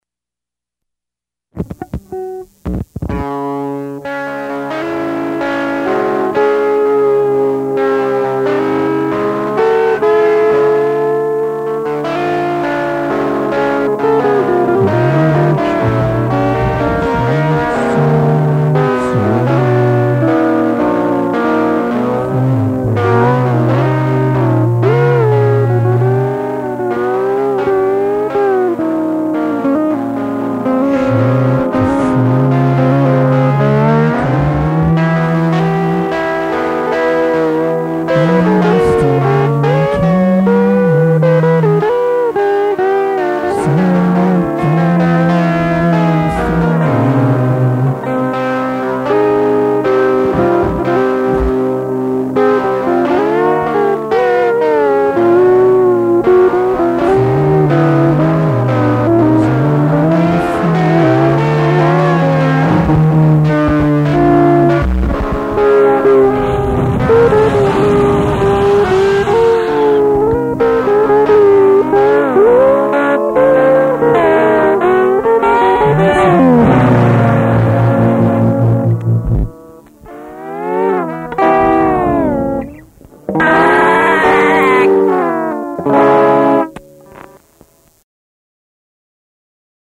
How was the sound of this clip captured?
recorded by the band at Studios 509